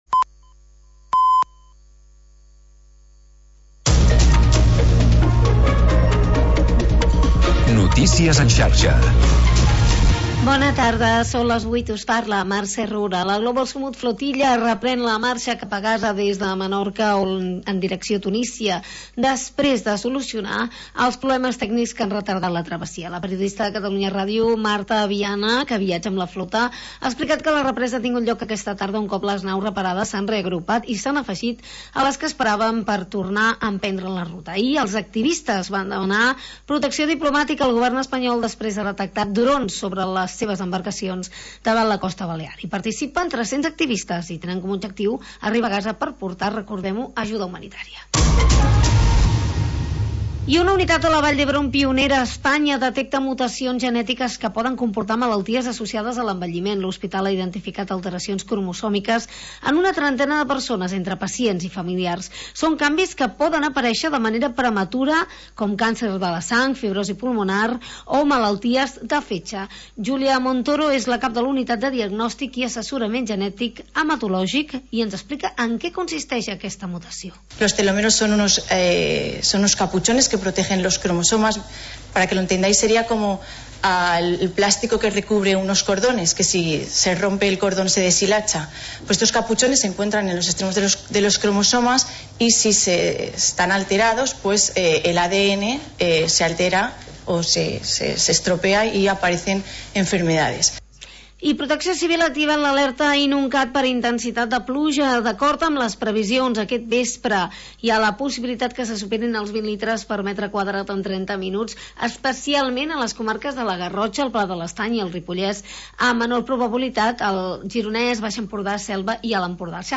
Espai per la música independent